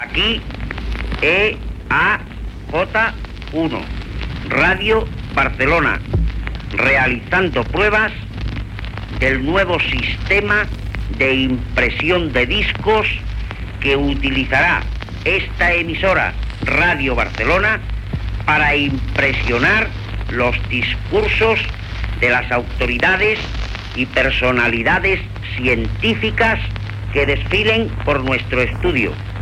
Extret de Crònica Sentimental de Ràdio Barcelona emesa el dia 8 d'octubre de 1994.